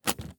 TrunkOpen.wav